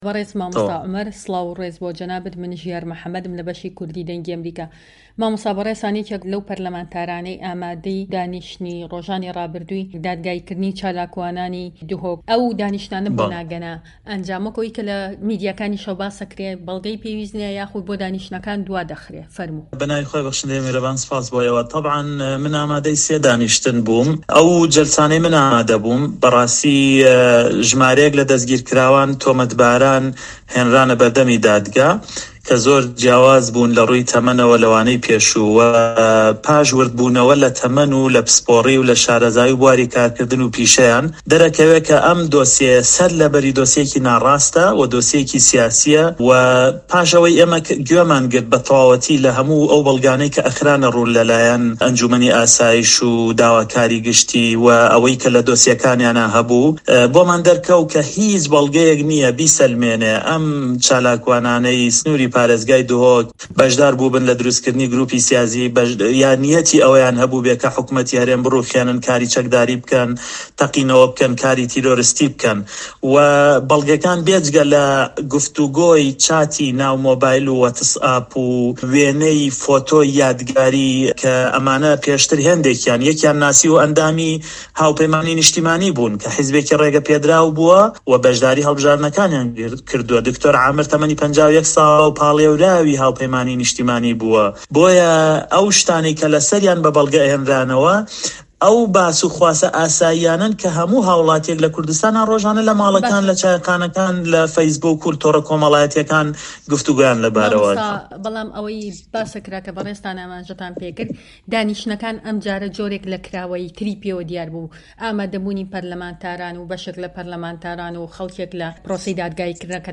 دەقی وتووێژەکەی
لەگەڵ عومەر گوڵپی ئەندامی پەرلەمانی هەرێمی کوردستان لە فراکسیۆنی کۆمەڵی دادگەری